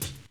Wu-RZA-Hat 13.WAV